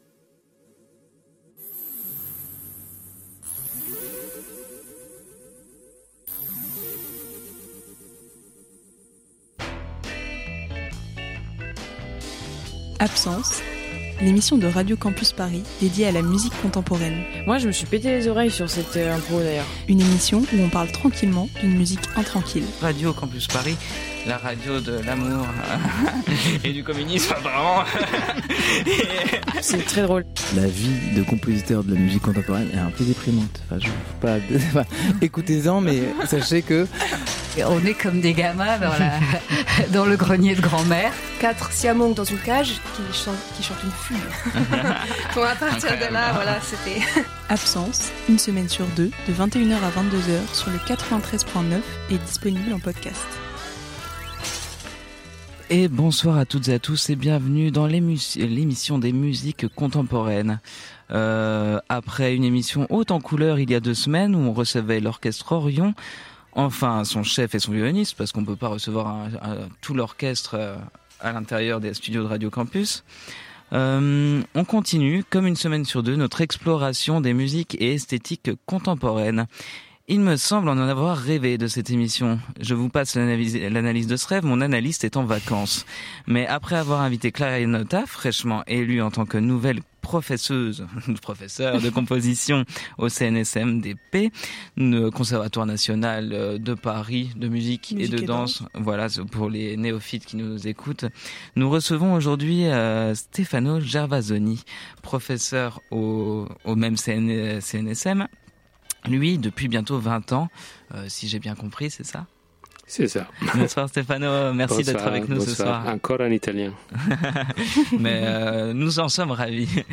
Musique contemporaine underground